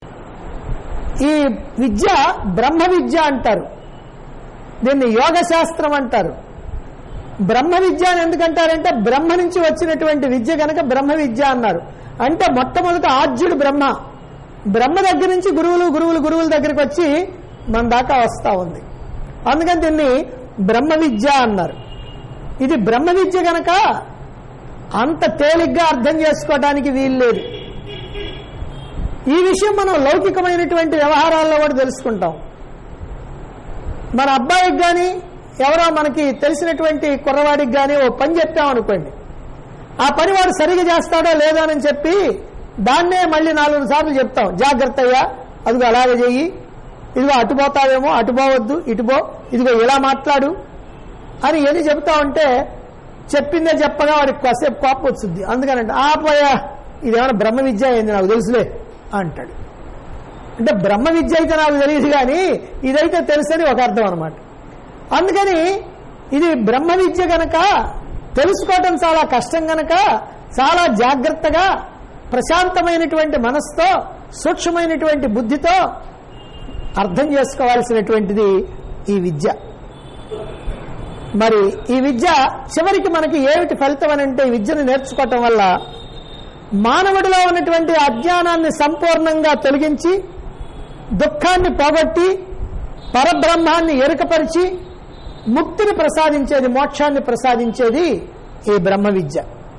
Discourse Conducted At Chilakaluripet, Guntur Dt. Andhra Pradesh.